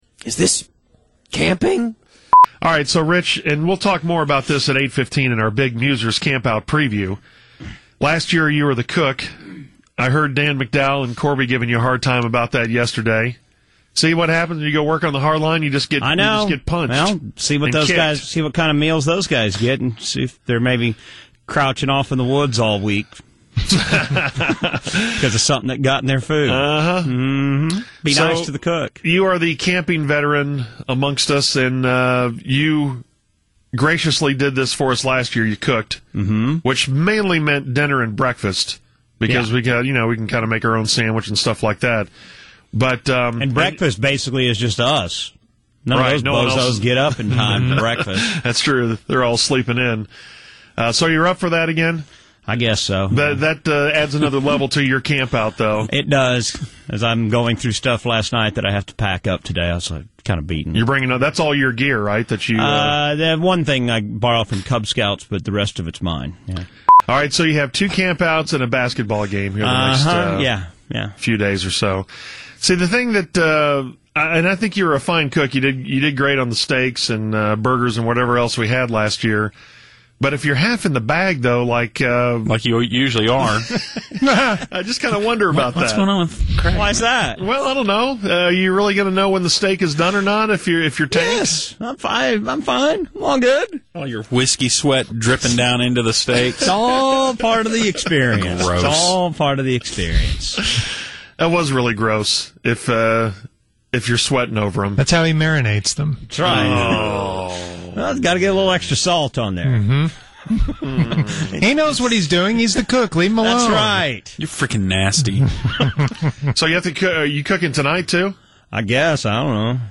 PS – I had to crank the audio quality down a notch to slide under the size limit.
It runs three hours, and there are little non-sequitur drops inserted between segments to keep you entertained.